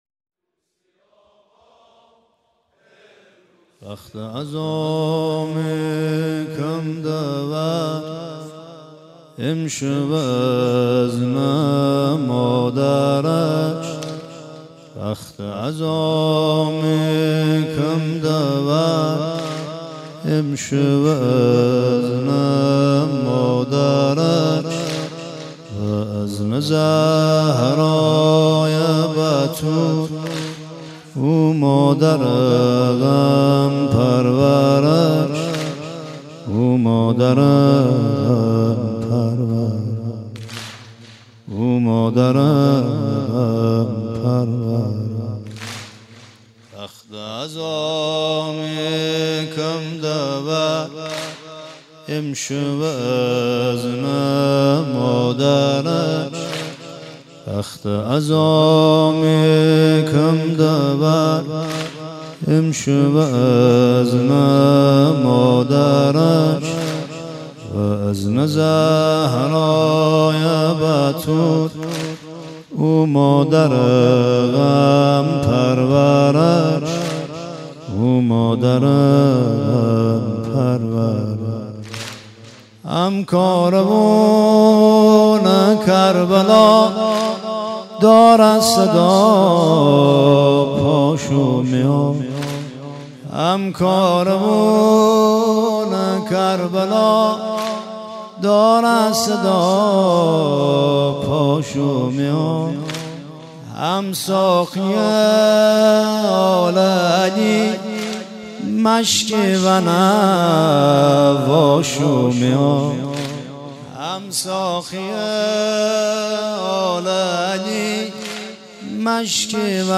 واحد لری
مداحی